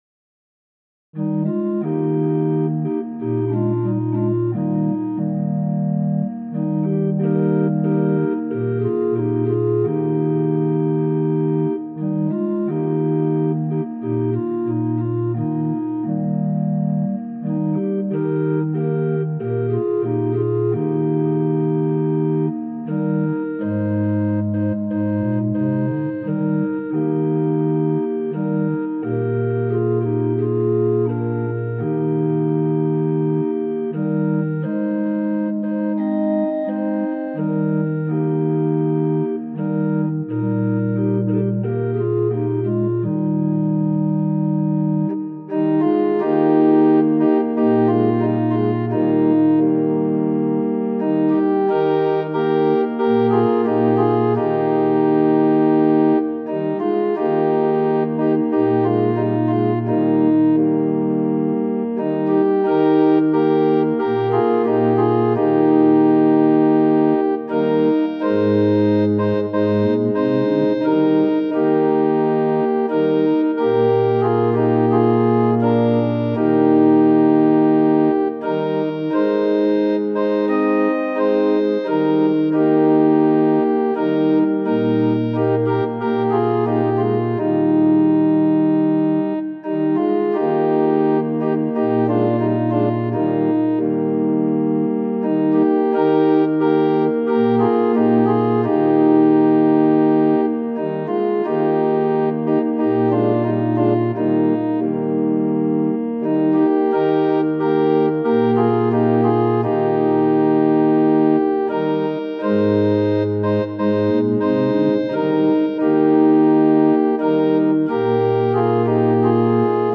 ♪賛美用オルガン伴奏音源：
・柔らかい音色部分は前奏です
・はっきりした音色になったら歌い始めます
・節により音色が変わる場合があります
・間奏は含まれていません
Tonality = Es
Pitch = 440
Temperament = Equal